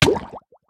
Minecraft Version Minecraft Version snapshot Latest Release | Latest Snapshot snapshot / assets / minecraft / sounds / entity / player / hurt / drown3.ogg Compare With Compare With Latest Release | Latest Snapshot
drown3.ogg